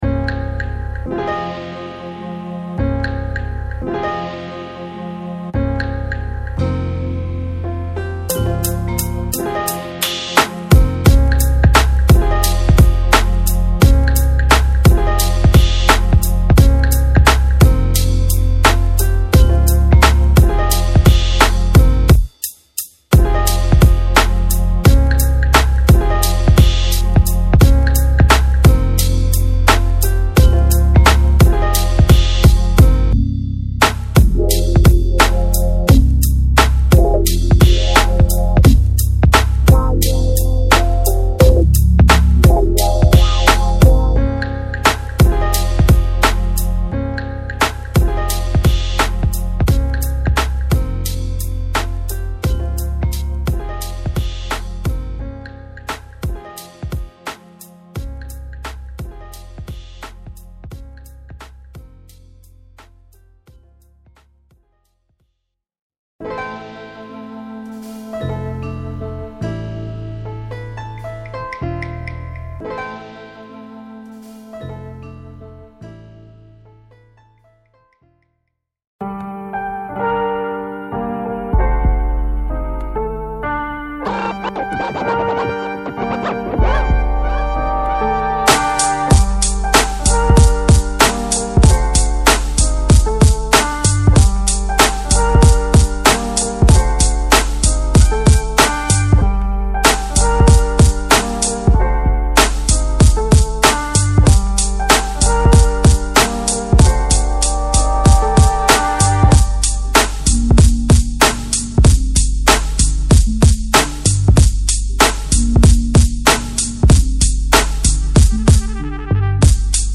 Tempo Labelled 60-108 BPM